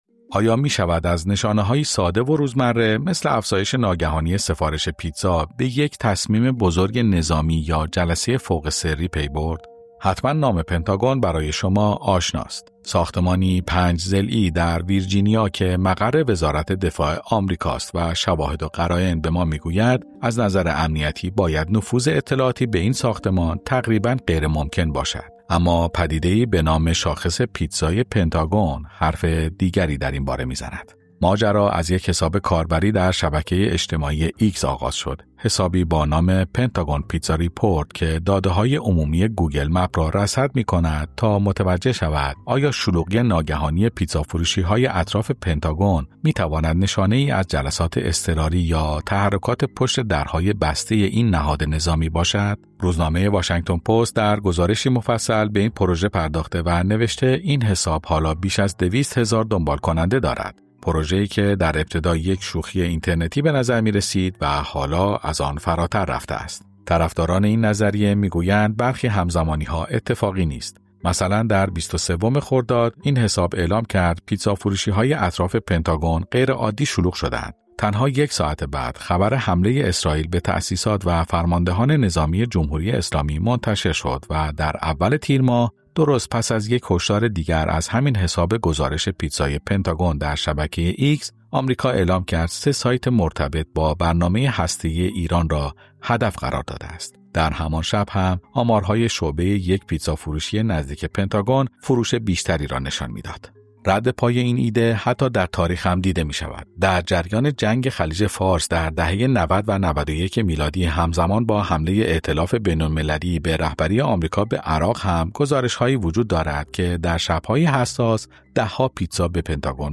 وقتی تعداد سفارش پیتزا در این منطقه زیاد می‌شود، برخی آن را صرفاً یک شب شلوغ نمی‌دانند، بلکه نشانه‌ای از یک تصمیم نظامی قریب‌الوقوع می‌دانند. در این گزارش می‌شنوید که چگونه یک شاخص غیرمعمول، به ابزاری پنهان برای پیش‌بینی وقایع مهم تبدیل شده است.